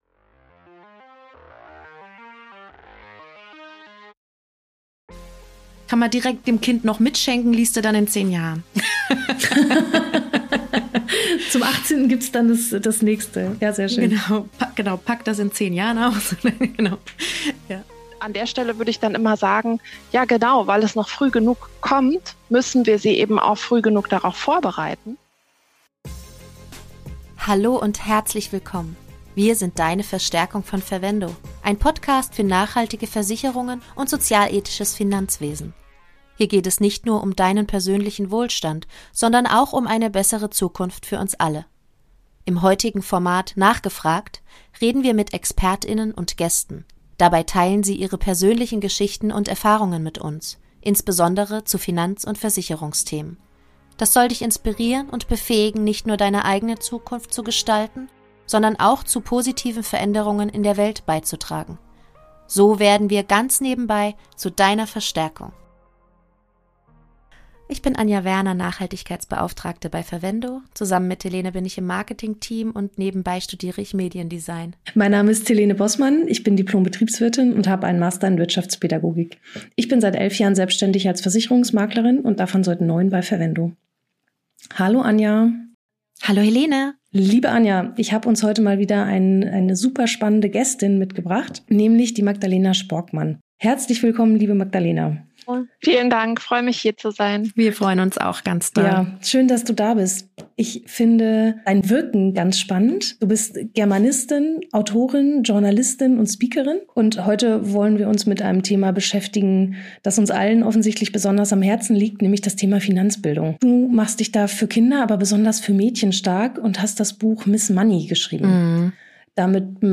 Ein Gespräch über finanzielle Gerechtigkeit, kluge Konsumentscheidungen und das Selbstbewusstsein, über Geld zu sprechen – ganz ohne Scham.